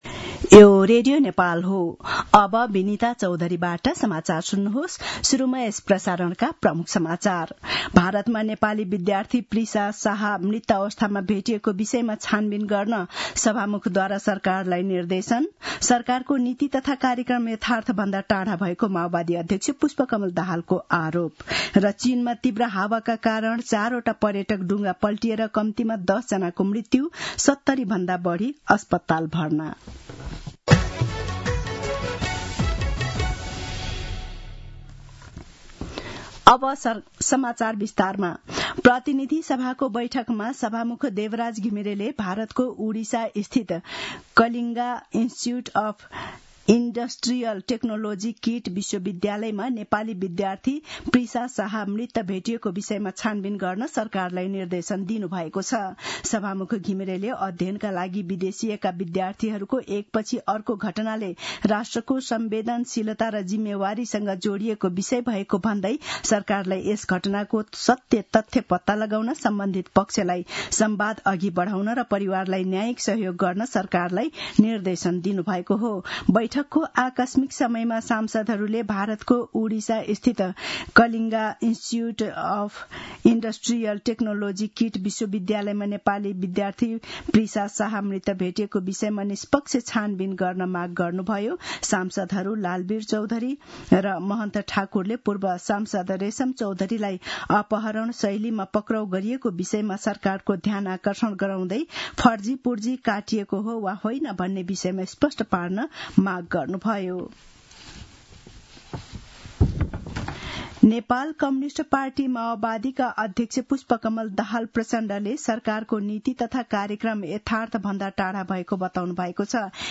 दिउँसो ३ बजेको नेपाली समाचार : २२ वैशाख , २०८२
3-pm-Nepali-News-1-22.mp3